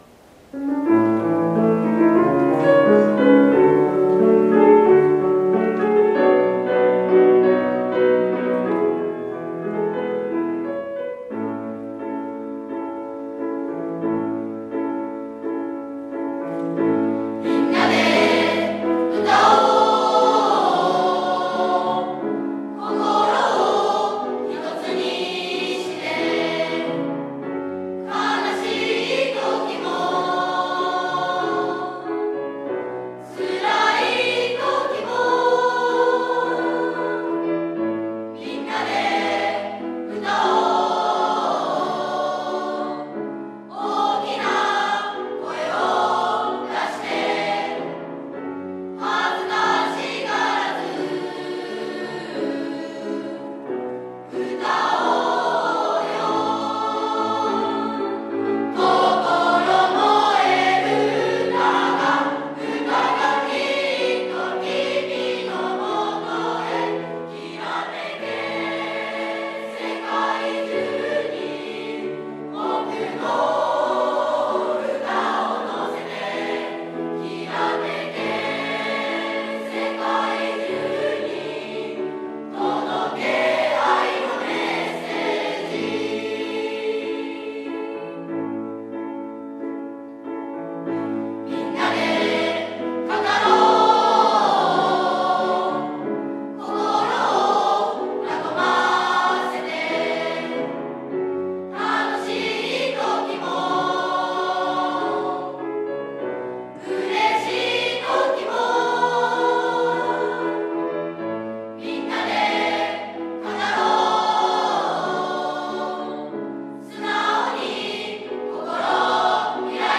「生徒が作るホームページ」 作成 1年2組書記委員 合唱コンクール1-2課題曲 合唱コンクール1-2自由曲